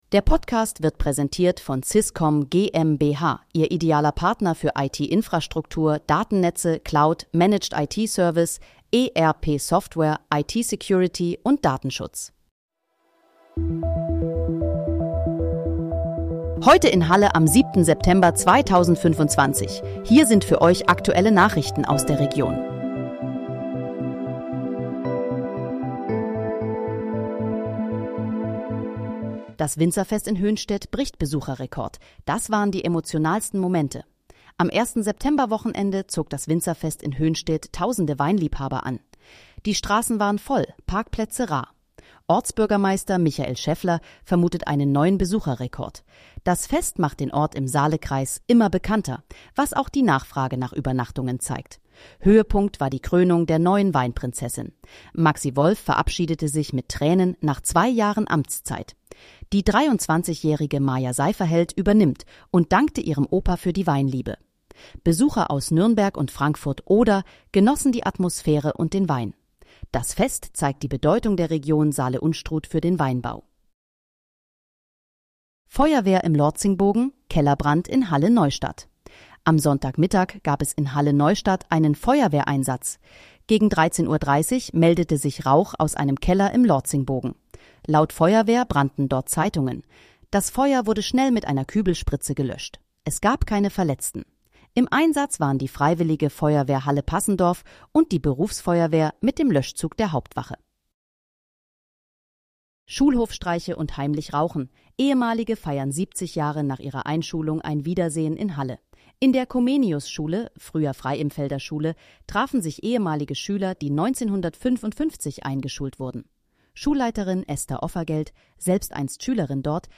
Heute in, Halle: Aktuelle Nachrichten vom 07.09.2025, erstellt mit KI-Unterstützung
Nachrichten